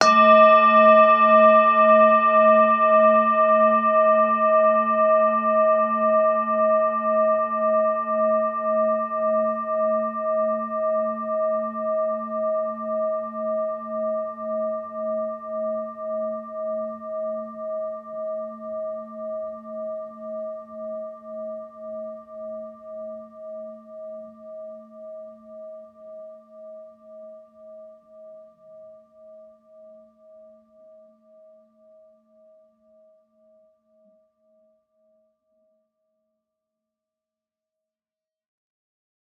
bowl1_mallet1-A3-ff.wav